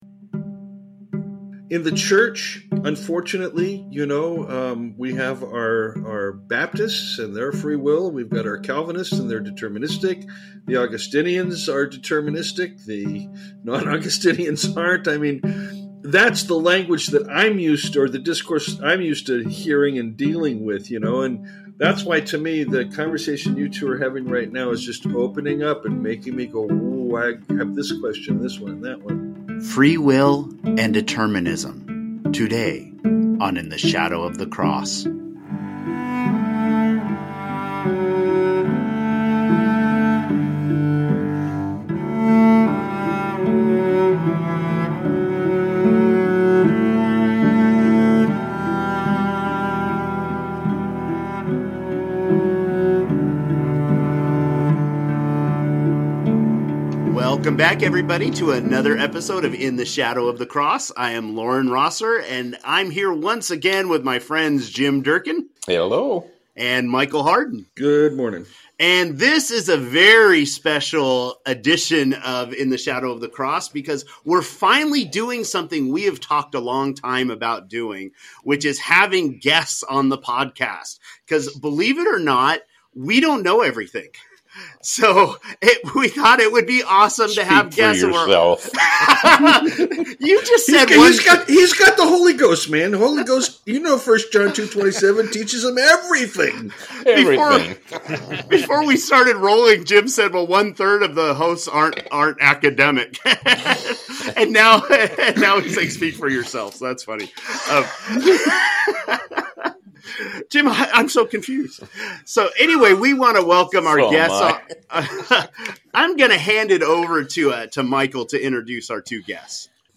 a fascinating debate on the topic of free will.